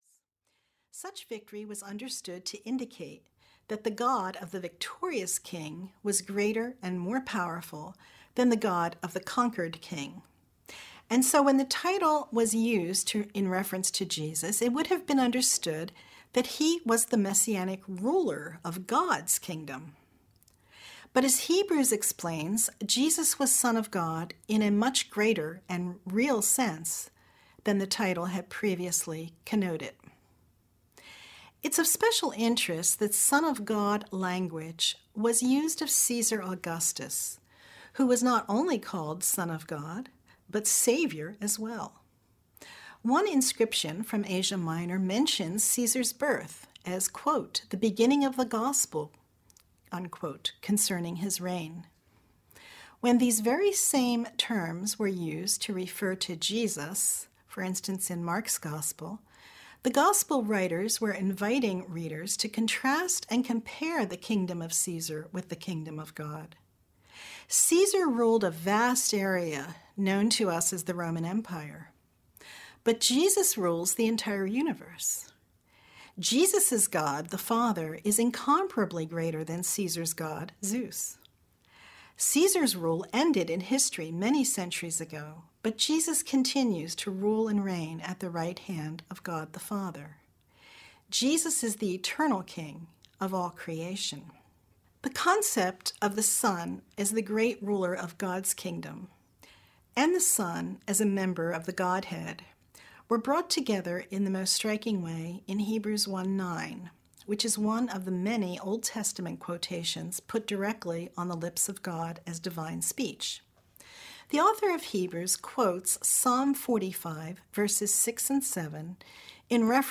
Letters to the Church: Audio Lectures Audiobook